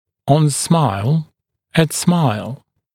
[ɔn smaɪl] [ət smaɪl][он смайл] [эт смайл]при улыбке, при улыбании